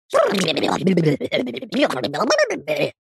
Звуки мультяшного голоса
Мультяшная тарабарщина